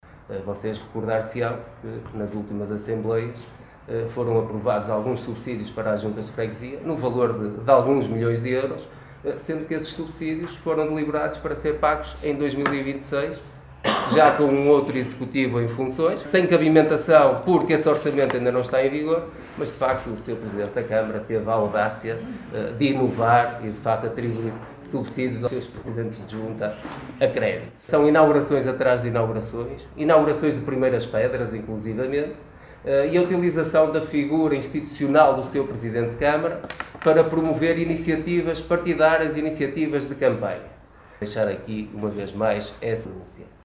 O PS de Barcelos apresentou, na tarde desta segunda-feira em conferência de imprensa, o compromisso eleitoral para os próximos 4 anos.